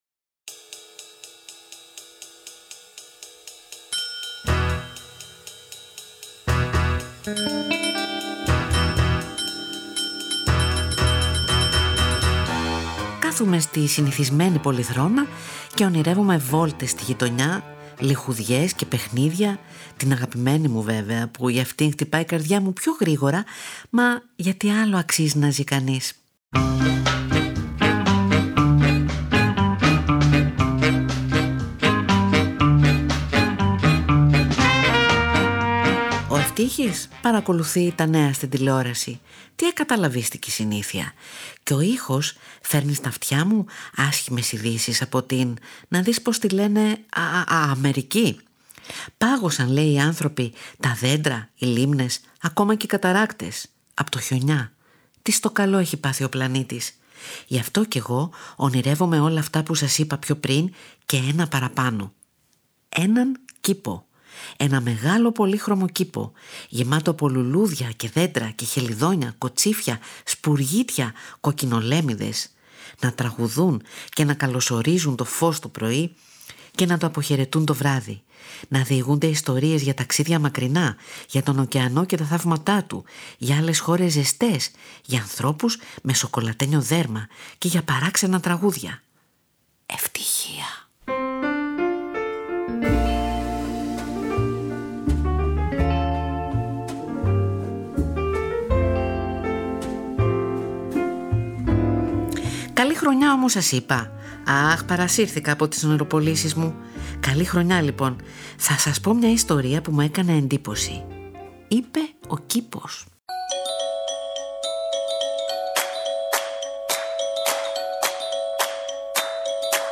Στο πικάπ γυρίζουν: Peter Gabriel, Tori Amos, Van Morrison, Laura Mvula, Ibrahim Ferrer, Talking Heads μεταξύ άλλων μια και τους απασχόλησαν κάποτε τα ίδια ερωτήματα.
Ο Κατ μαθαίνει από τον Ευτύχη και μάς αφηγείται κι από μια ιστορία που διάβασε και πάντα την συνδέει με ένα αγαπημένο τραγούδι.